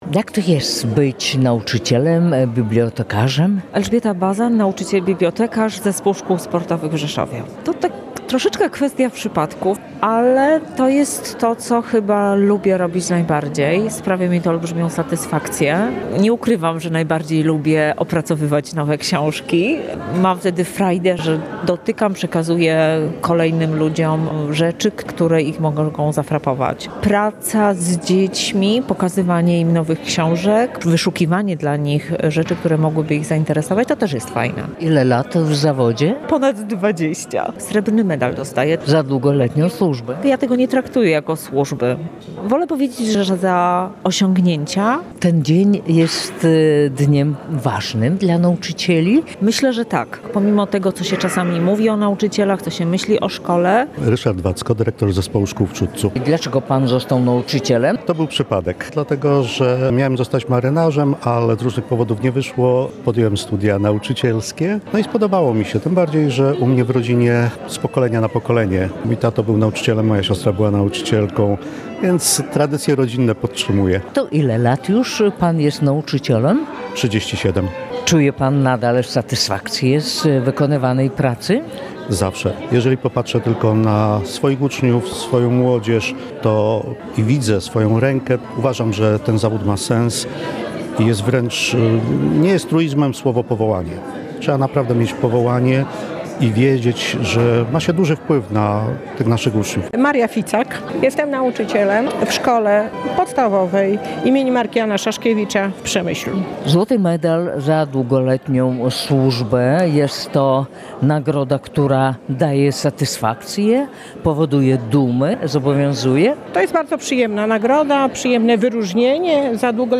Uroczyste obchody Dnia Edukacji Narodowej w Rzeszowie